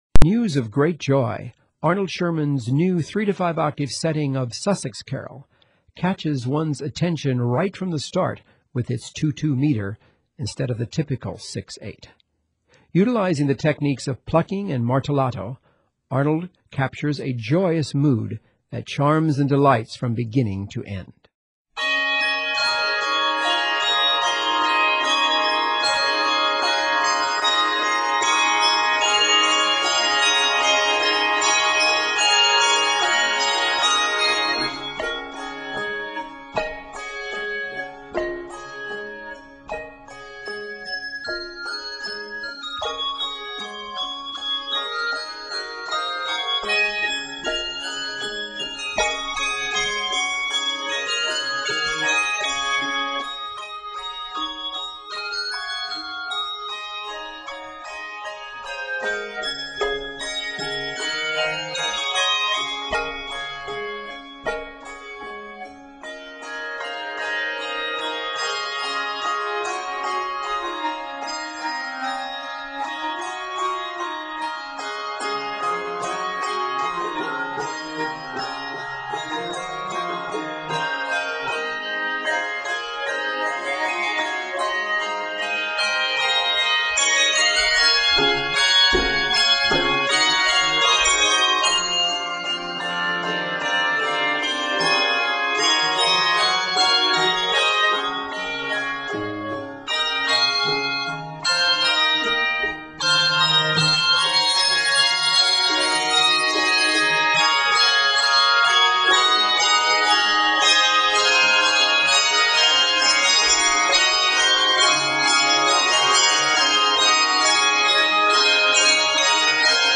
Utilizing the technique of plucking and martellato